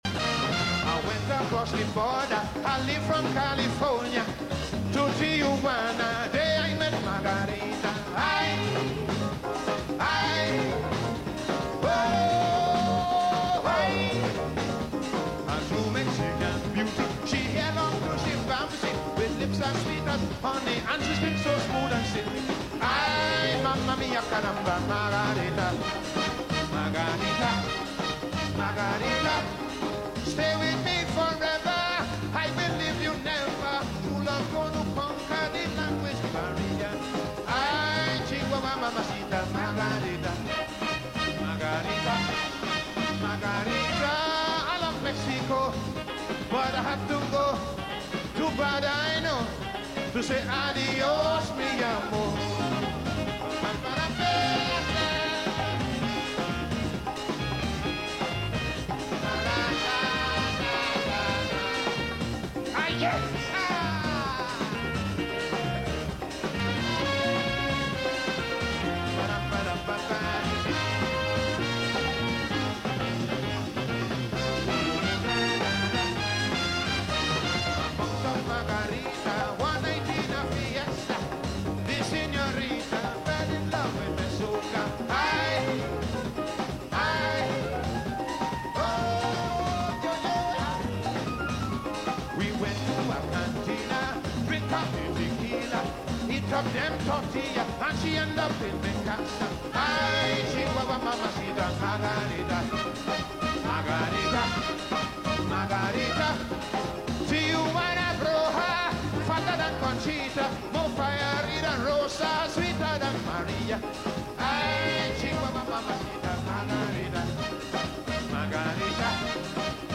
calypso. He is indeed a great performer.